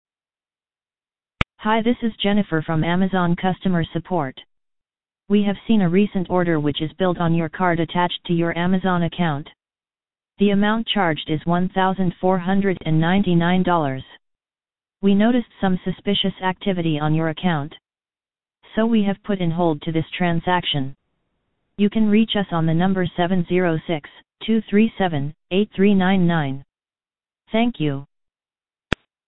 Robocall :arrow_down: